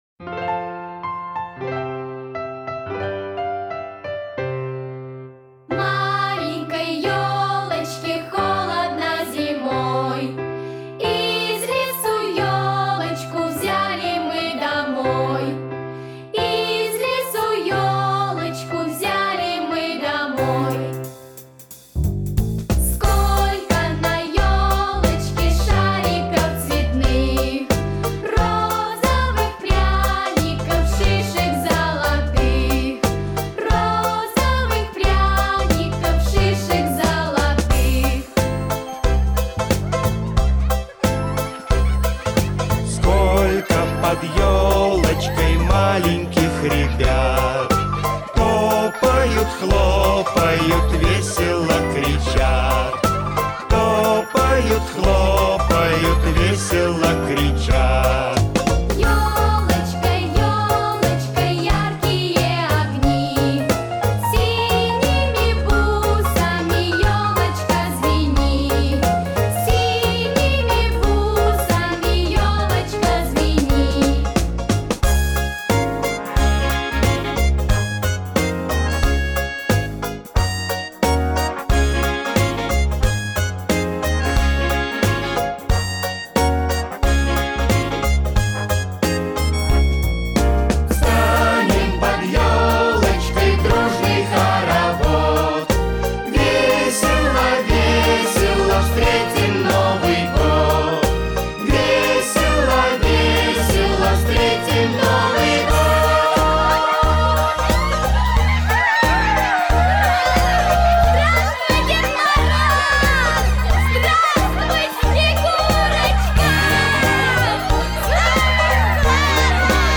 Главная » Песни » Новогодние песни